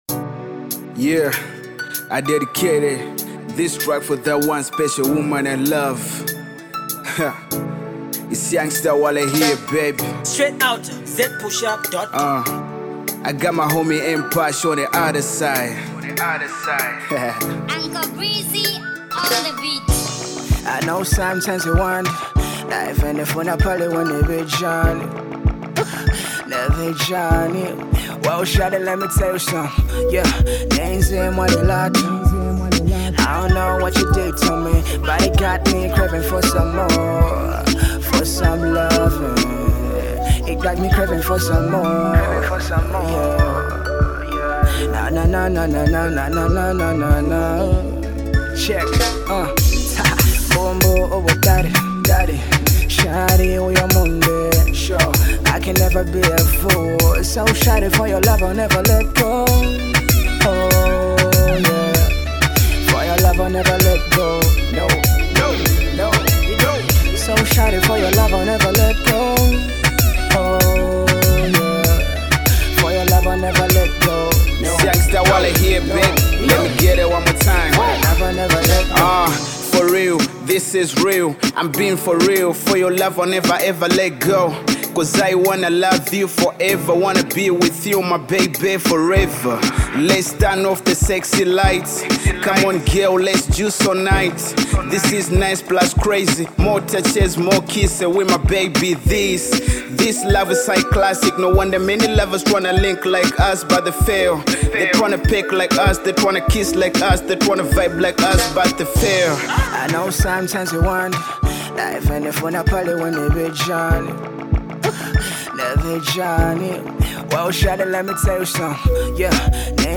rap act